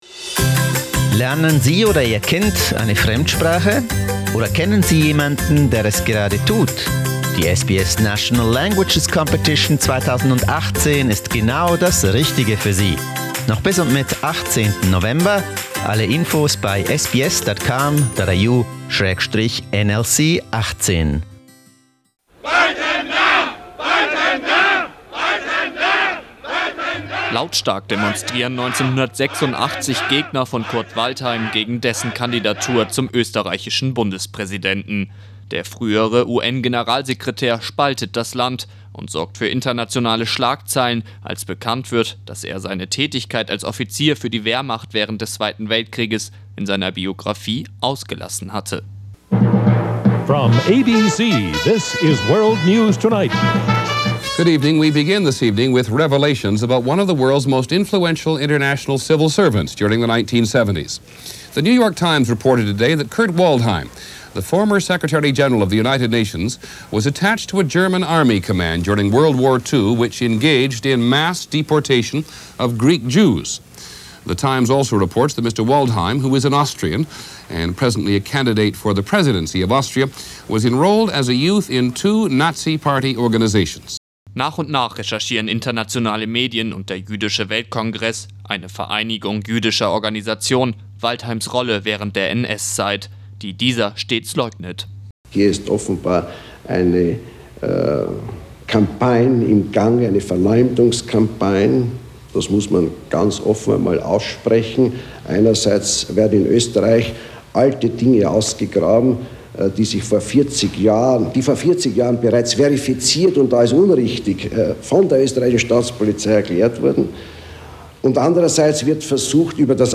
The film documents the affair from the 80s and is currently running throughout Australia at the Jewish Film Festival. We talked to the producer Ruth Beckermann about the film.